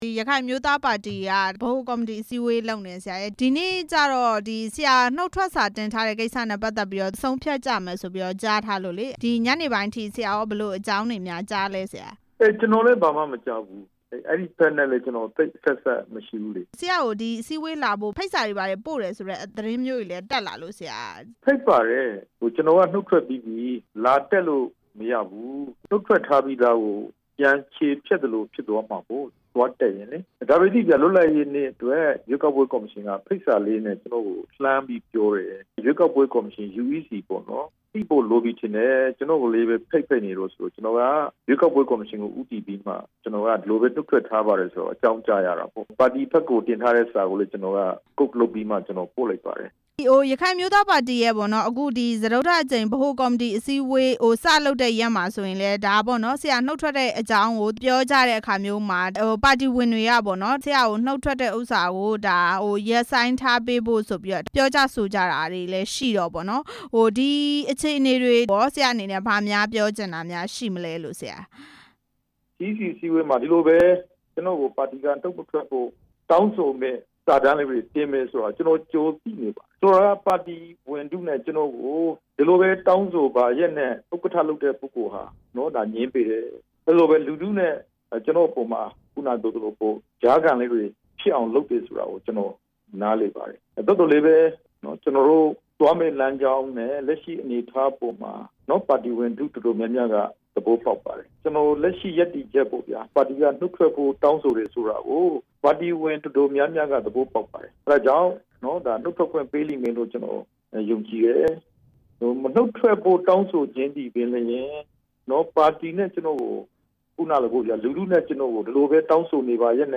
ဒေါက်တာအေးမောင် နုတ်ထွက်မယ့်အကြောင်း မေးမြန်းချက်